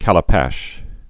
(kălə-păsh, kălə-păsh)